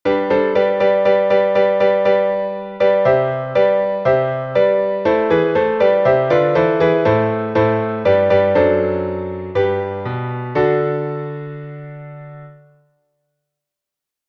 Глас 3